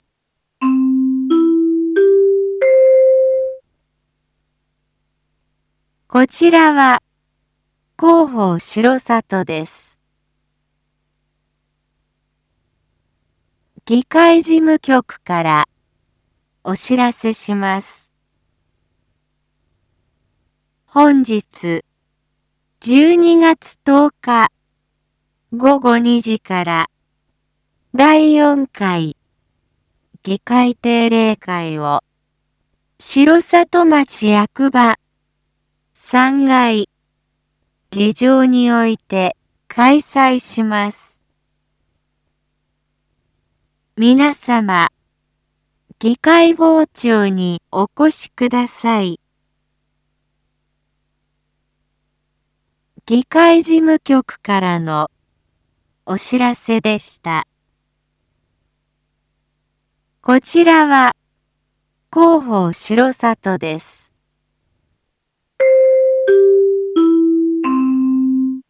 Back Home 一般放送情報 音声放送 再生 一般放送情報 登録日時：2024-12-10 07:01:10 タイトル：第４回議会定例会⑥ インフォメーション：こちらは広報しろさとです。